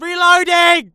Combat Dialogue